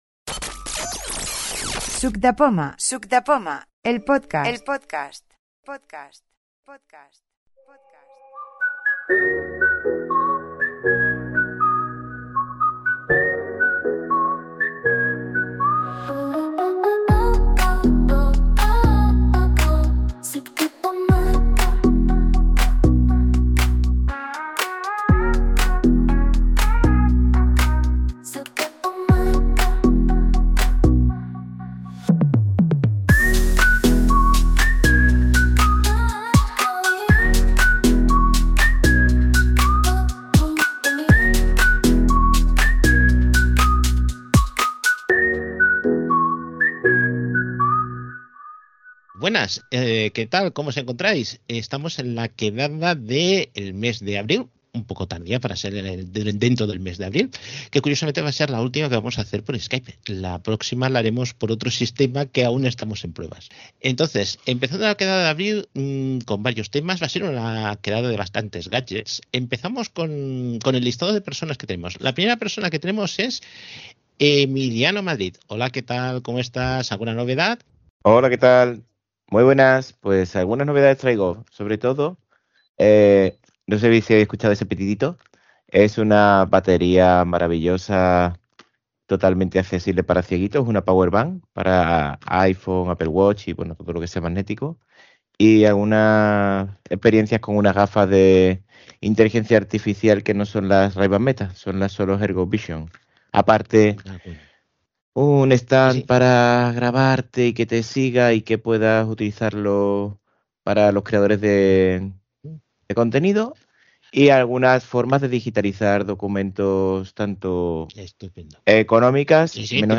Por lo que si os gusta crear música y tenéis curiosidad por saber cómo se comporta la IA en este aspecto, así como cuánto se puede llegar a conseguir con ella, en esta audio demo encontraréis todo lo necesario para iniciaros en este mundo con la herramienta de creación musical Suno AI. Ah, y también podréis escuchar varios ejemplos de canciones creadas con ella. De hecho, las sintonías del inicio y del final de la audio demo también forman parte de esos ejemplos.